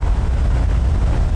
Platformboosterrising Sound Effect
platformboosterrising.mp3